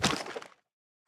sounds / mob / strider / step1.ogg
step1.ogg